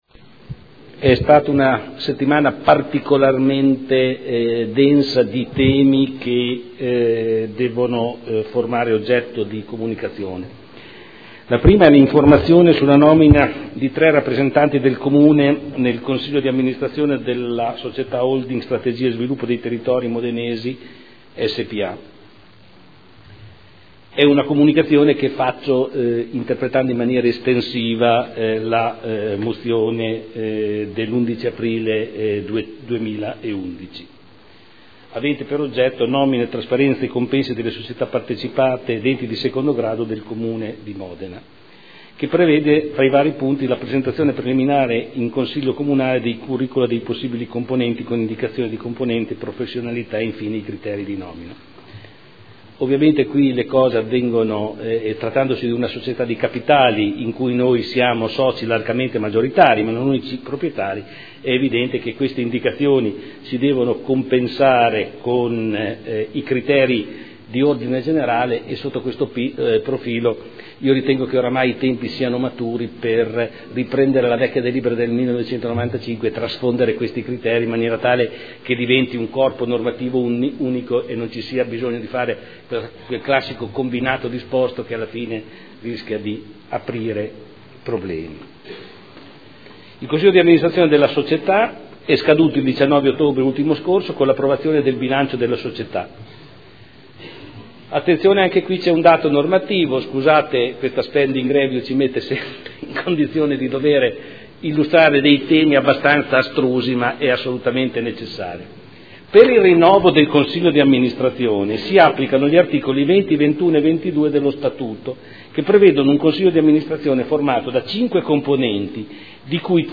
Giorgio Pighi — Sito Audio Consiglio Comunale
Seduta del 22/10/2012. Comunicazione del Sindaco sulla nomina di 3 rappresentanti del Comune di Modena nel c.d.a. della società Holding Strategia e Sviluppo dei Territori Modenes i s.p.a., sul tema prevenzione e contrasto del fenomeno della contraffazione, su Premio Anci Sicurezza Urbana e su premio "Bonissima" Città di Modena.